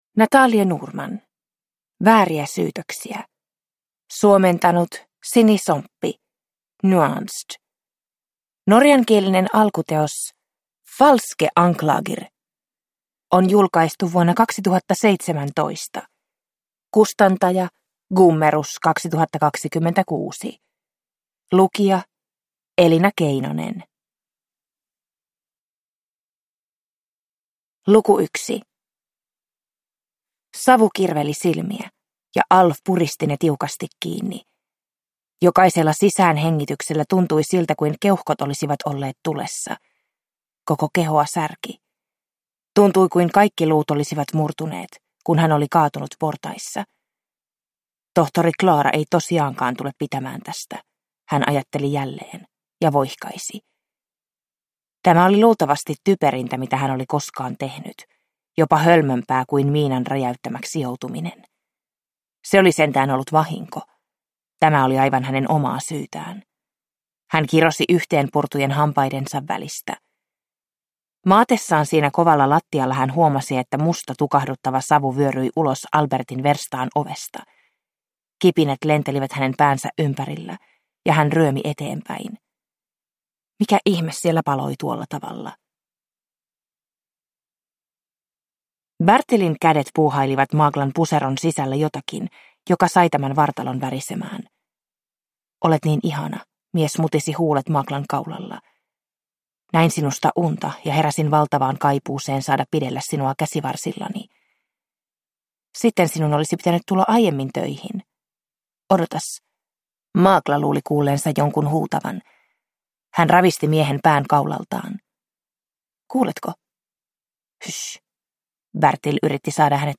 Vääriä syytöksiä – Ljudbok